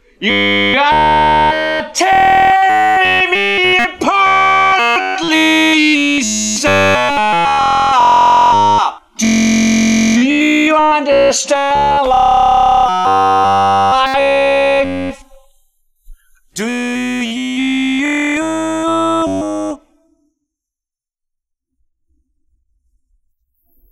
Stuttering Butcher applies stutter edits and glitch on an audio file according to settings.
theroom1-stuttered.wav